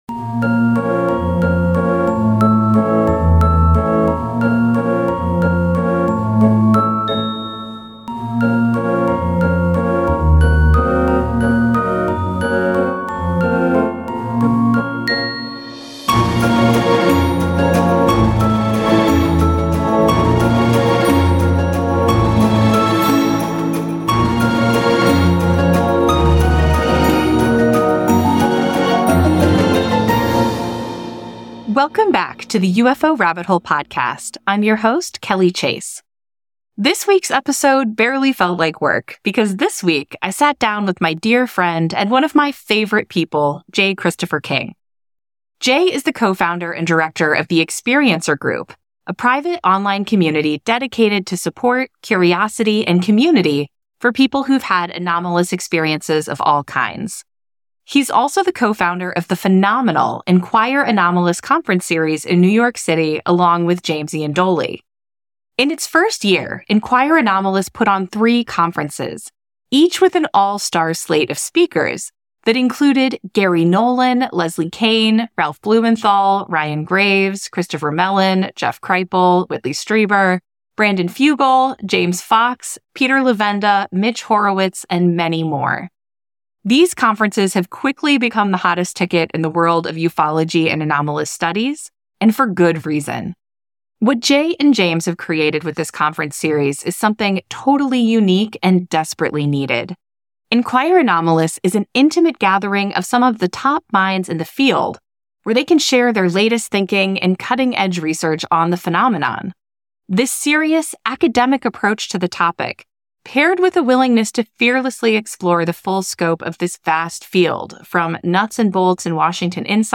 Social Sciences, Society & Culture, Science, Documentary